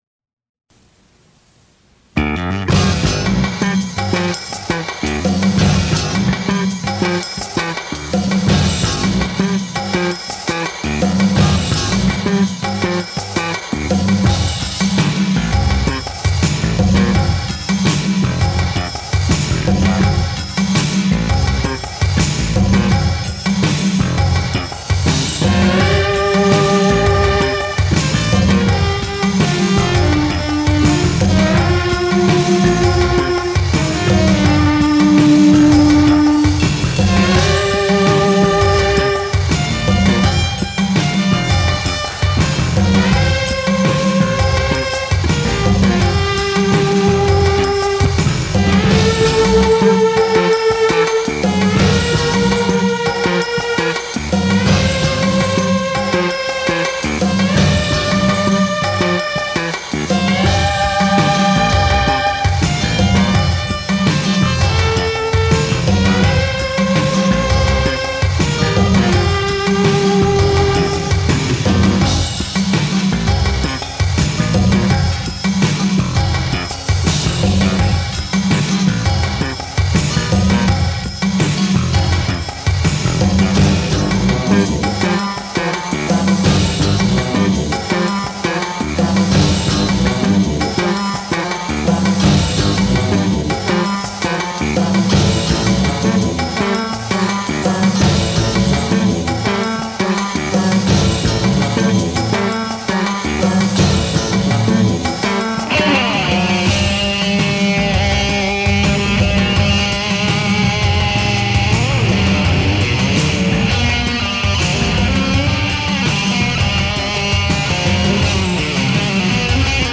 During that weekend the group recorded the ten songs on a cassette tape deck.
Bass guitarist
instrumental songs